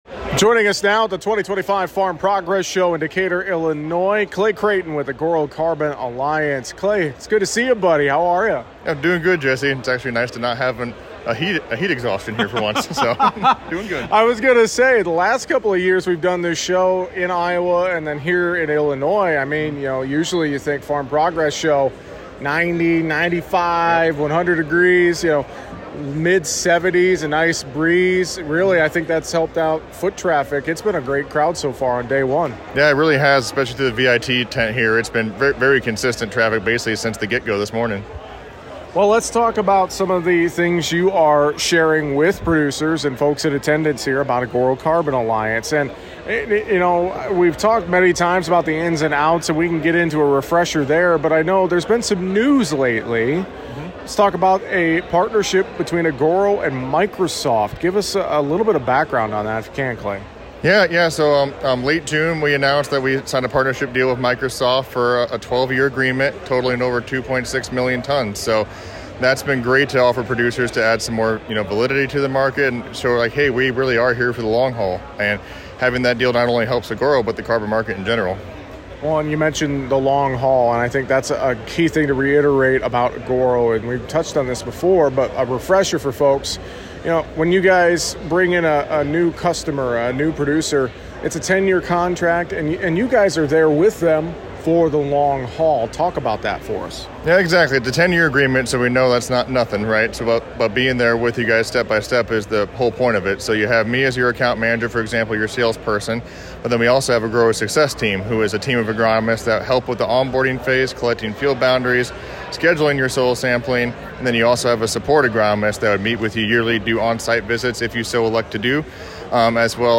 We talk about the latest news and updates from Agoro Carbon Alliance, their process, building soil health and much more